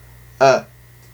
• /œ/ is open-mid near-front rounded [œ] (
Its rounding is compressed.